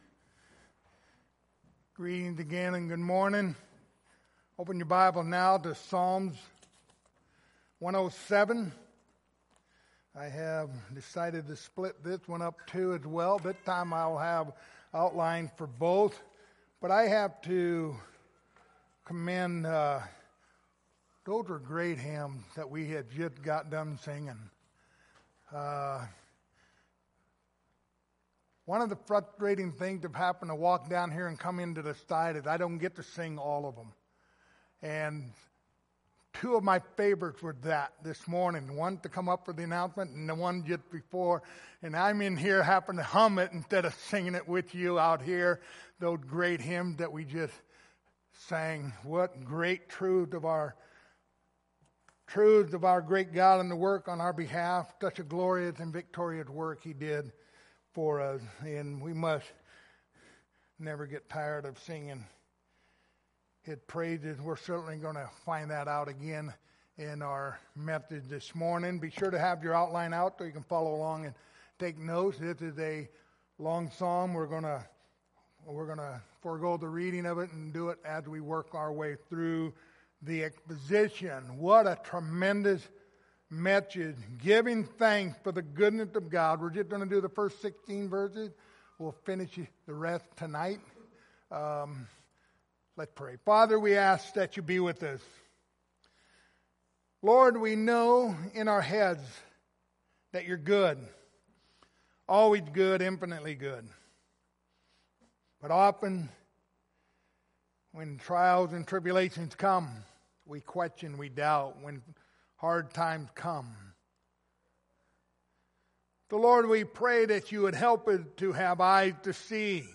Passage: Psalms 107:1-16 Service Type: Sunday Morning